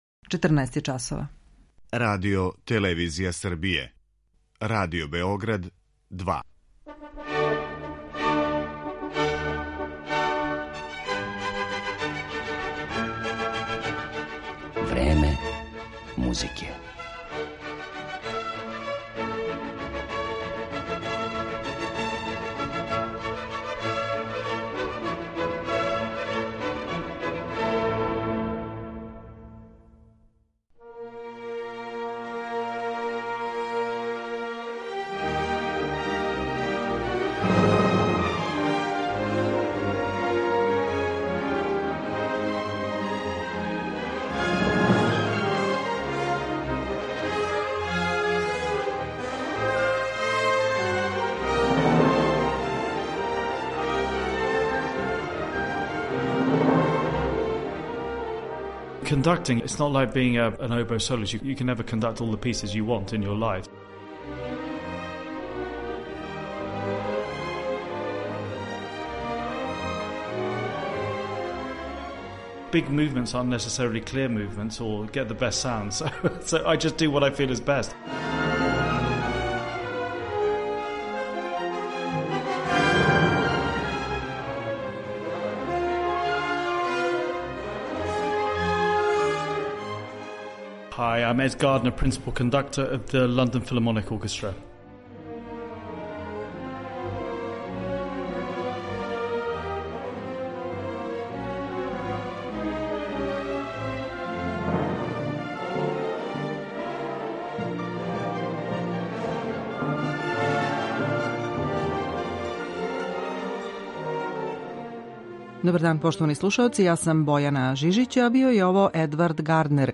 Ovog velikog britanskog umetnika, koji je nedavno postao glavni dirigent jednog od najboljih orkestara na svetu, Londonske filharmonije, predstavićemo i kroz ekskluzivni intervju, snimljen sa njim pre nekoliko nedelja u Bukureštu.